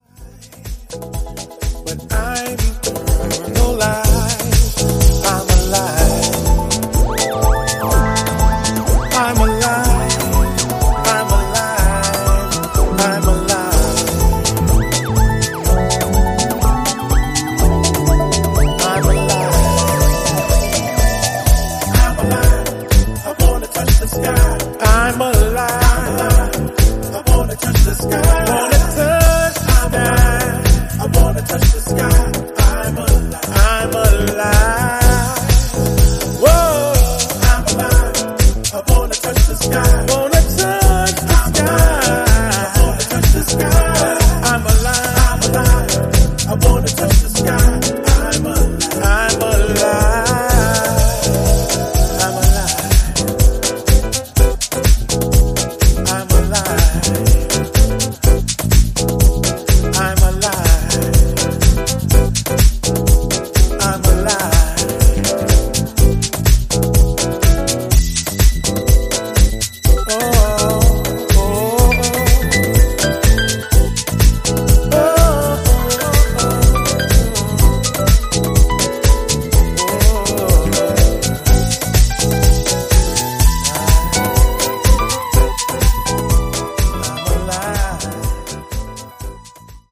Extended Vocal Mix